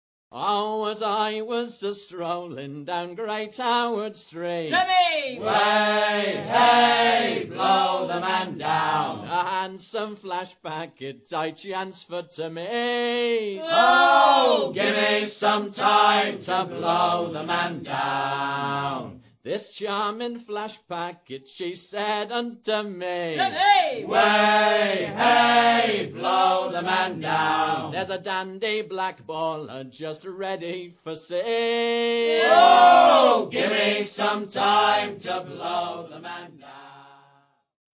Songs of the North Atlantic Sailing Packets